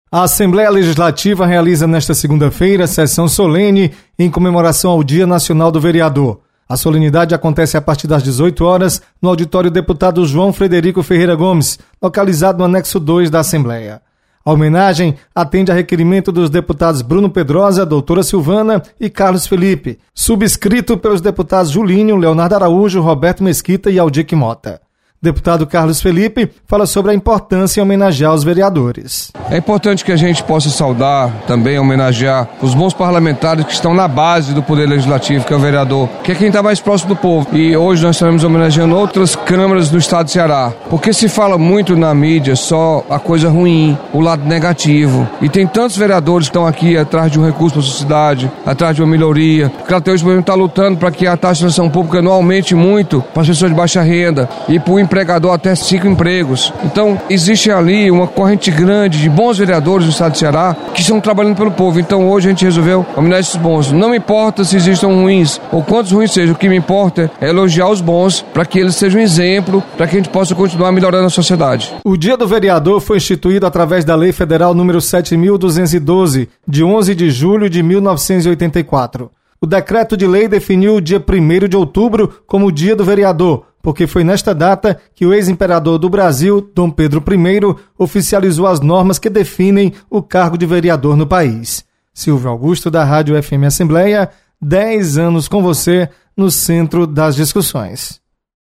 Solenidade